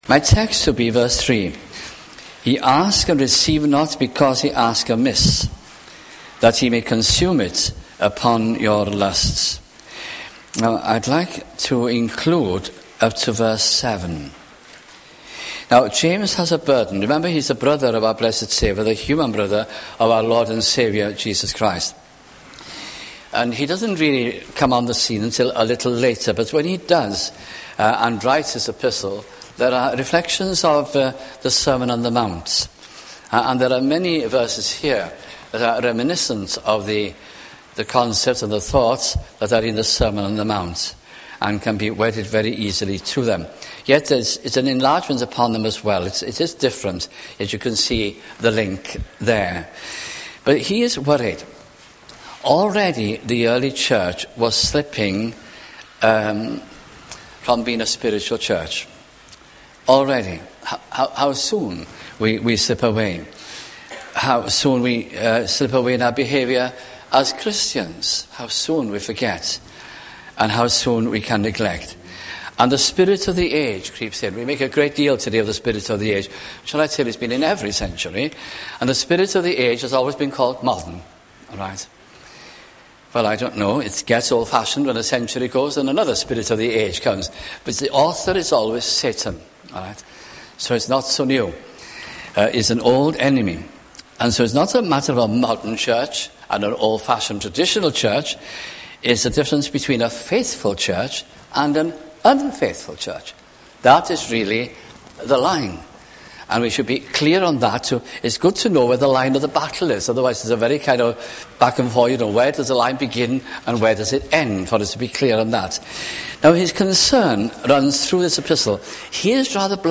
» James 2002 » sunday morning messages